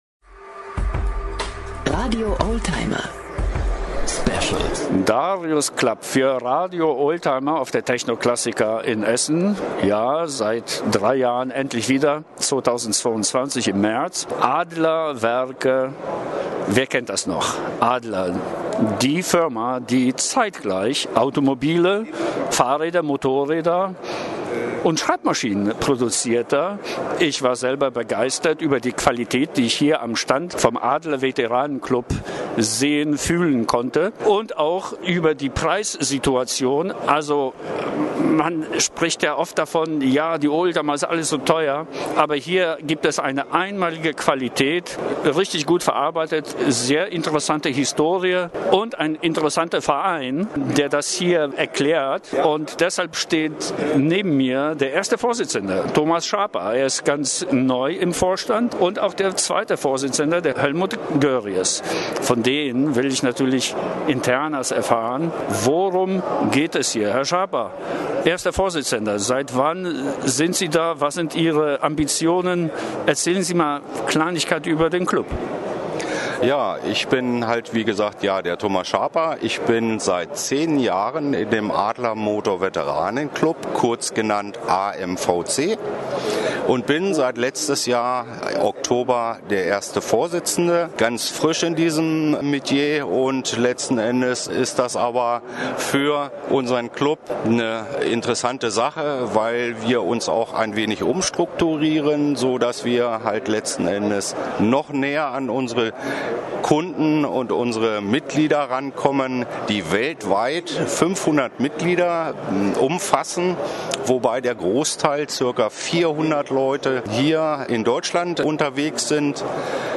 Interview von der Techno-Classica